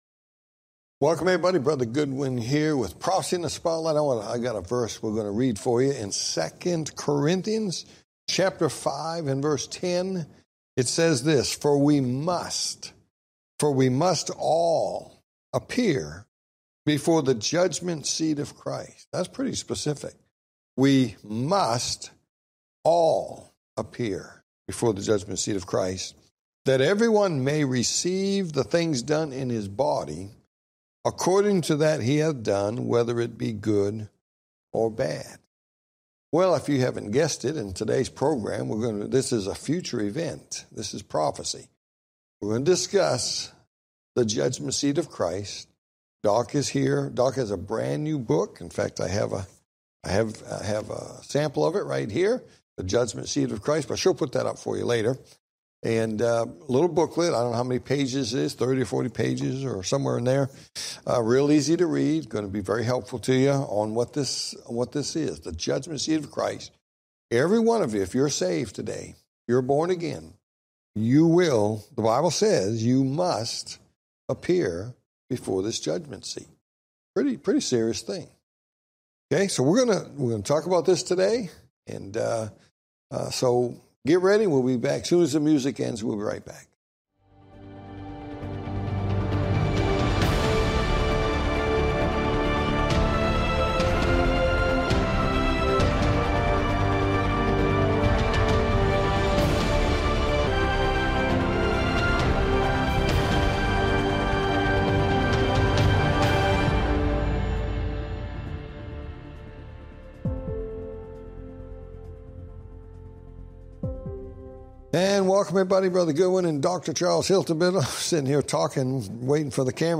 Talk Show Episode, Audio Podcast, Prophecy In The Spotlight and The Judgment Seat Of Christ And Questions For The Host Pt 1 on , show guests , about The Judgment Seat Of Christ,Bible Study,Bible Prophecy,Faith, categorized as History,News,Politics & Government,Religion,Society and Culture,Theory & Conspiracy